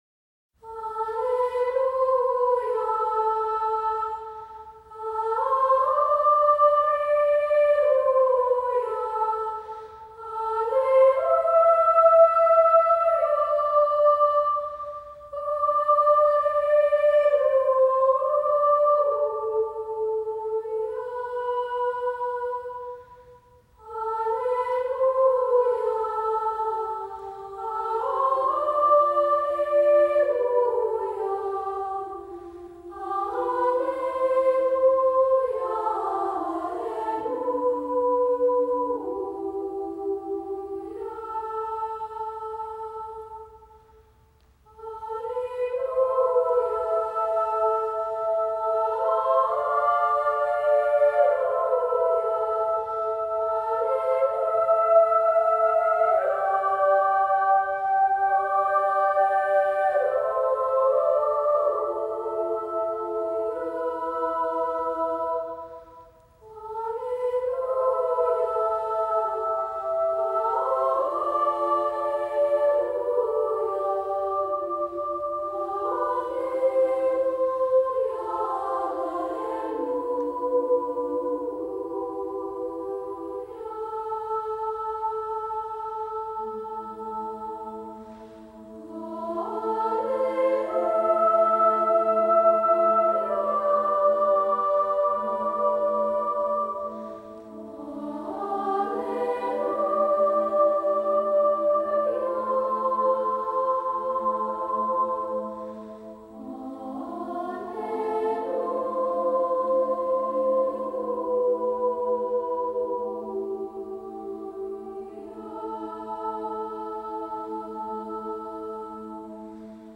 SSAA or TTBB